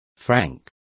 Also find out how timbra is pronounced correctly.